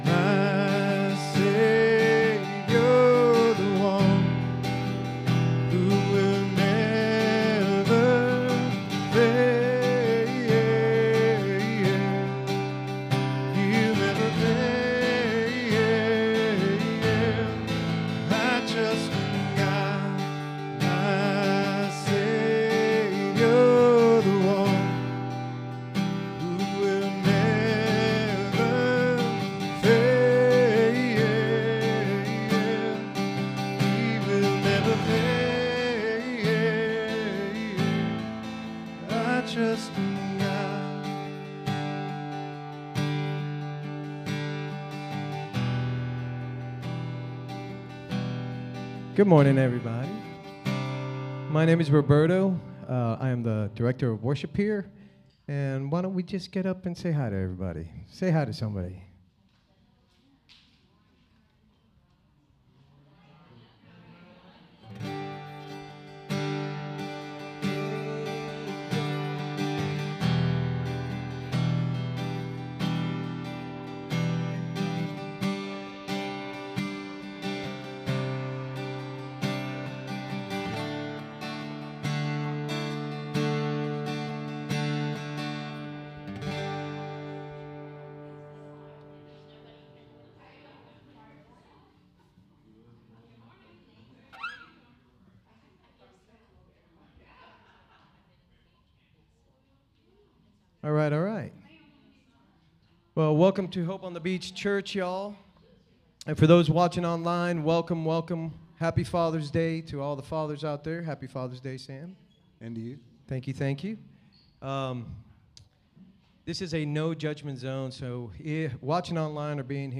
SERMON DESCRIPTION Jesus is at work doing a new thing in our life and yet so often we get stuck or return to the old thing that we know.